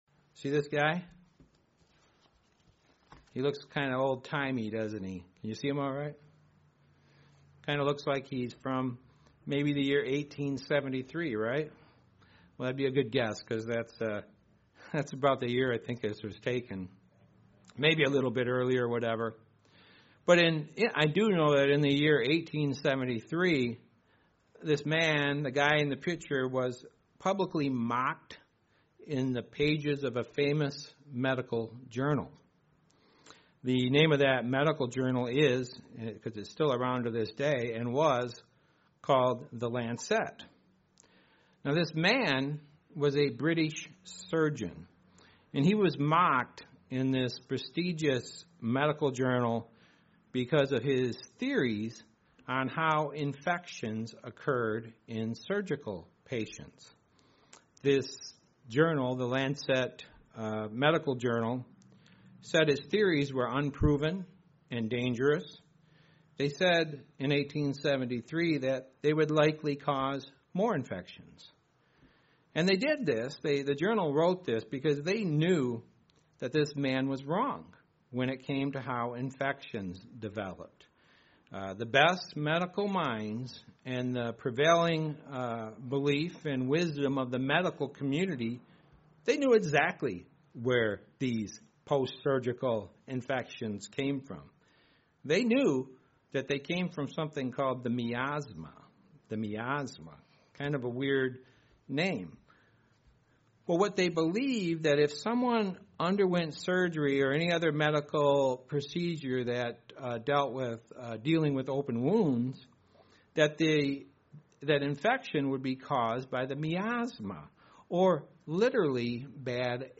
Do not forget to download the slide show that goes with this sermon or view it online